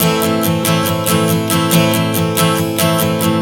Strum 140 Am 04.wav